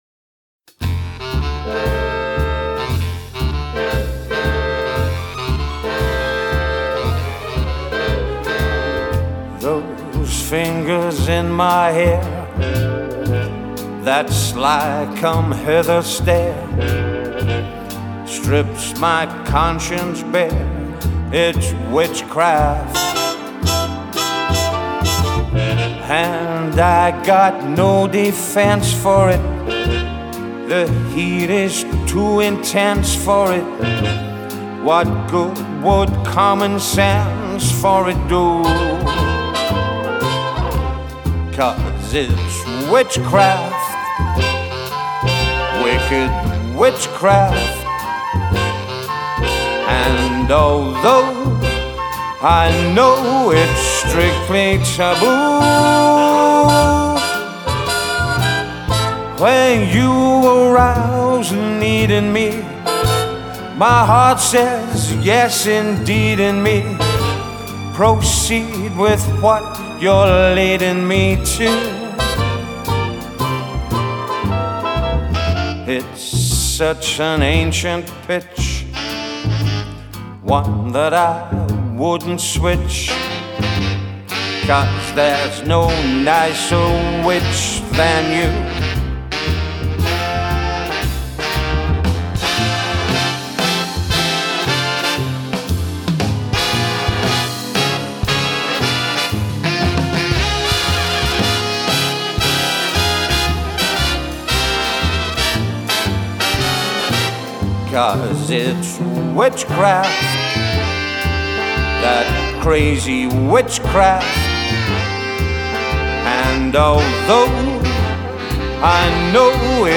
Swing and Rat Pack Singers
it really does sound like a big band backing him.